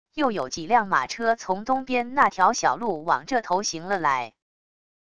又有几辆马车从东边那条小路往这头行了来wav音频生成系统WAV Audio Player